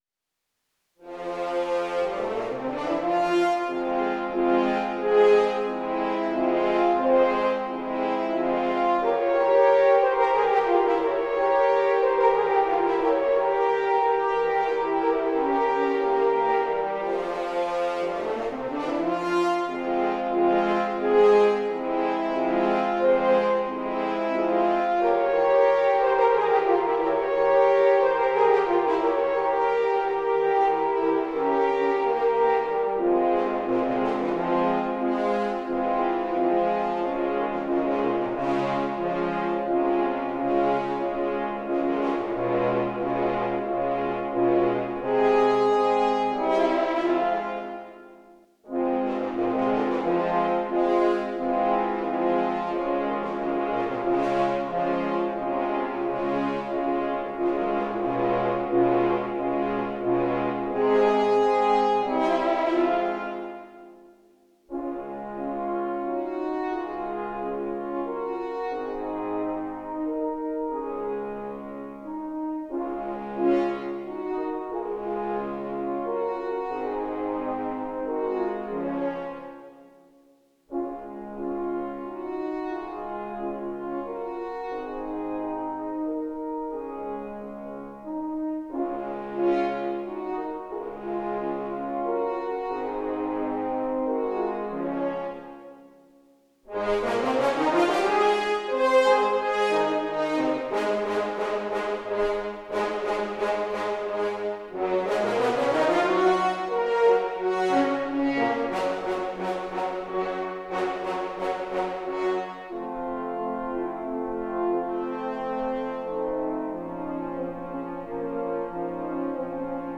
Walzer moderato. 9. c-moll für 4 Hörner
Comp : par L. v. Beethoven (ut mineur à ¾) [p. 9, 17, 25, 33] Walzer moderato.